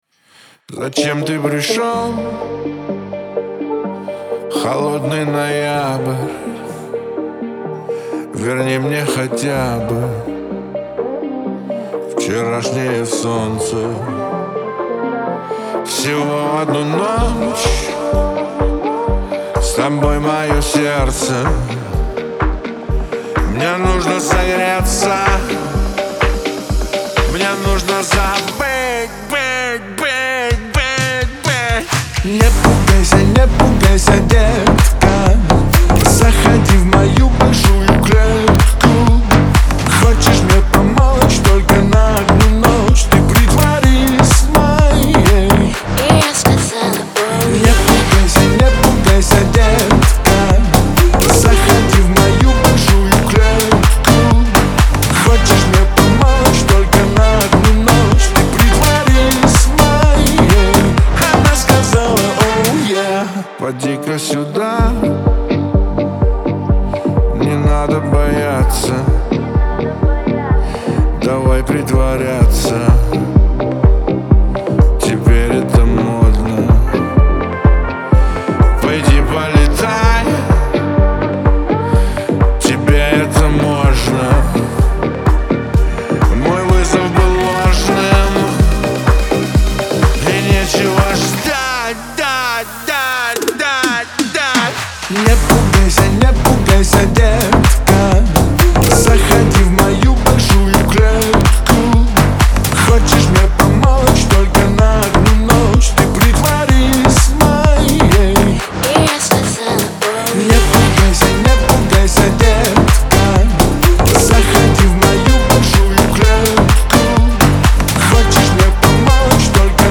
дуэт , грусть
поп